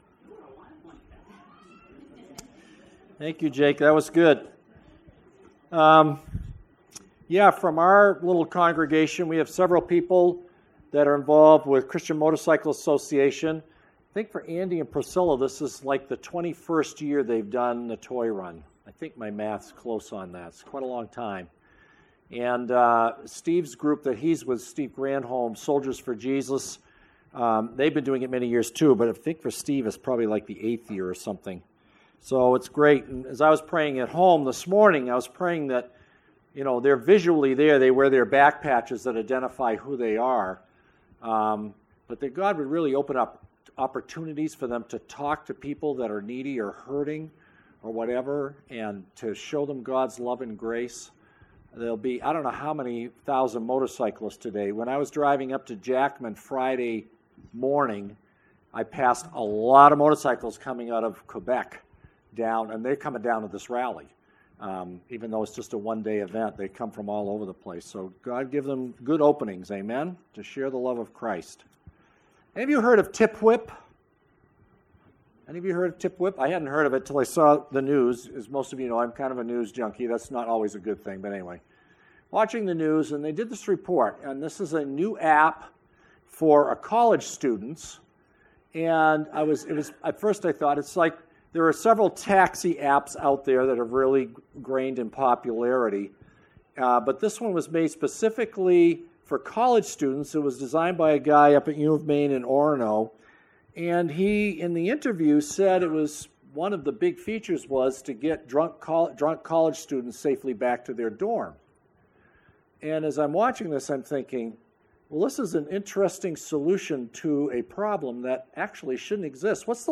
the message